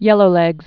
(yĕlō-lĕgz)